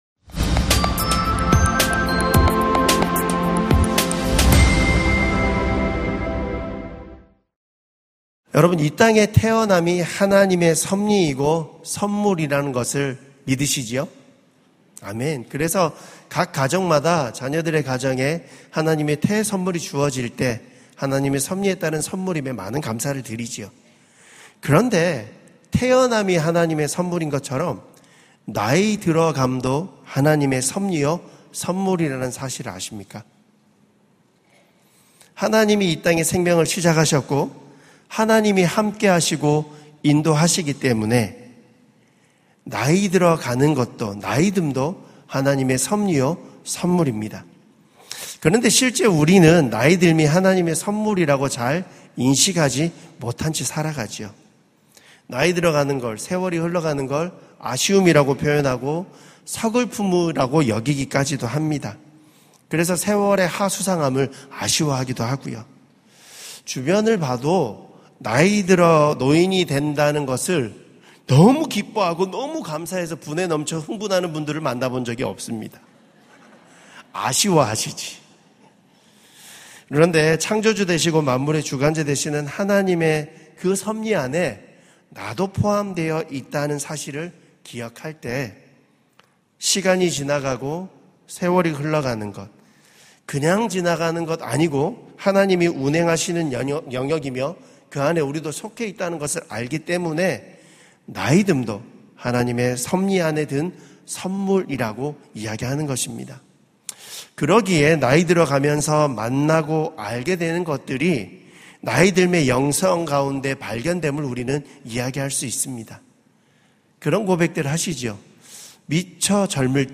설교 : 시니어예배